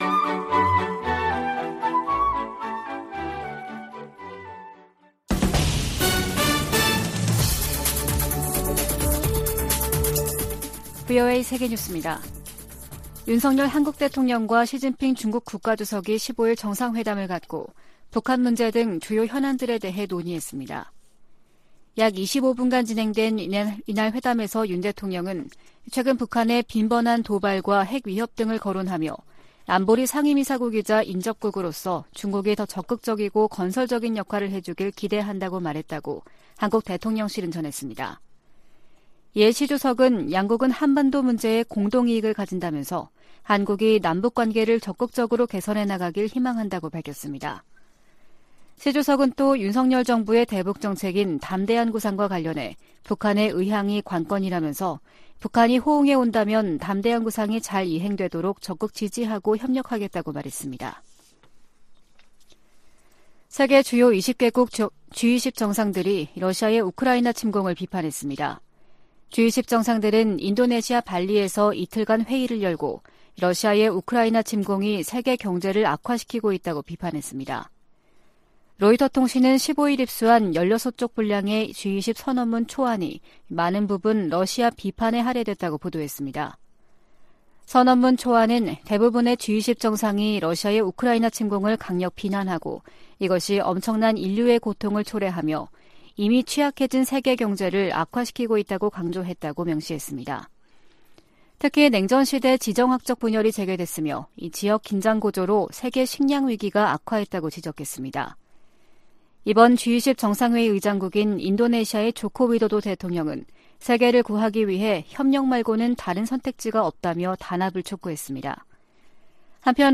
VOA 한국어 아침 뉴스 프로그램 '워싱턴 뉴스 광장' 2022년 11월 16일 방송입니다. 조 바이든 미국 대통령은 인도네시아에서 열린 미중 정상회담에서 시진핑 국가주석에게 북한이 핵실험 등에 나서면 추가 방위 조치를 취할 것이라고 말했다고 밝혔습니다. 미국과 중국의 정상회담에서 북한 문제 해법에 대한 견해차가 확인되면서 한반도를 둘러싸고 높아진 긴장이 지속될 전망입니다.